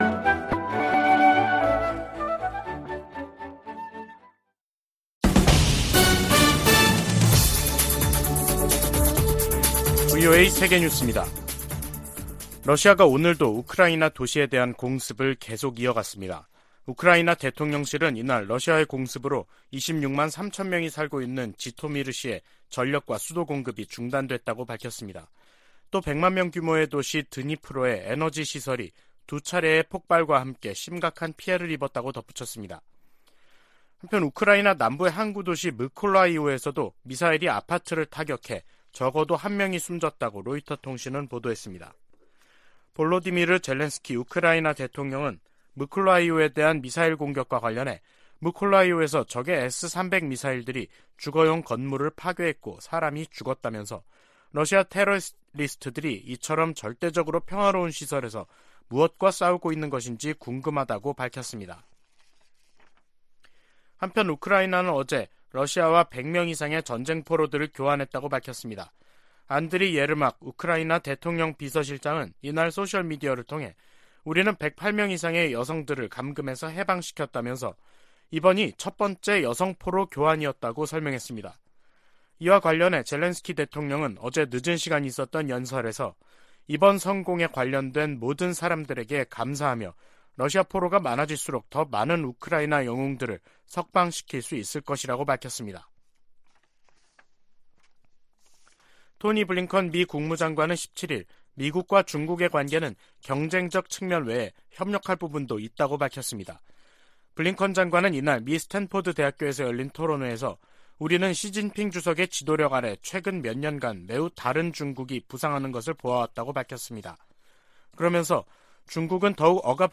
VOA 한국어 간판 뉴스 프로그램 '뉴스 투데이', 2022년 10월 18일 3부 방송입니다. 북한의 잇따른 도발은 무시당하지 않겠다는 의지와 강화된 미한일 안보 협력에 대한 반발에서 비롯됐다고 토니 블링컨 미 국무장관이 지적했습니다. 필립 골드버그 주한 미국대사는 전술핵 한반도 재배치론에 부정적 입장을 분명히 했습니다. 북한의 잇단 미사일 발사로 긴장이 고조되면서 일본 내 군사력 증강 여론이 강화되고 있다고 미국의 일본 전문가들이 지적했습니다.